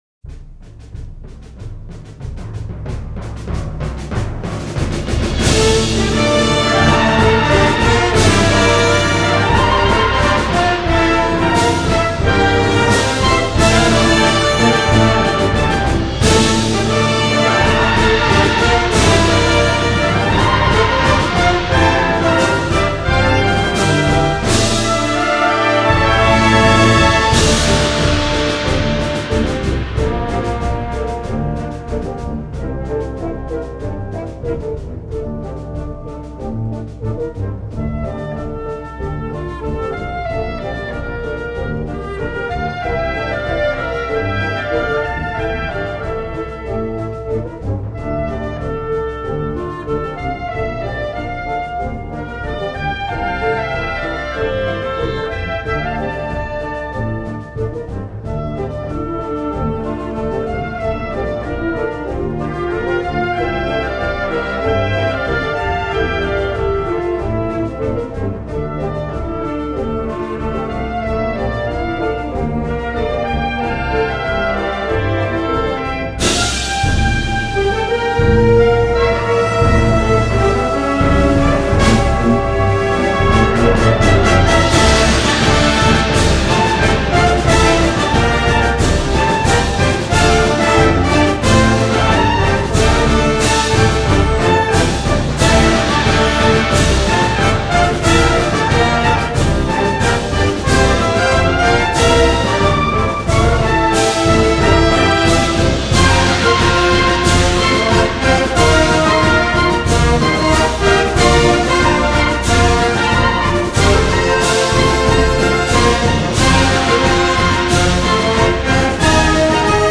Christian March
Gender: Christian marches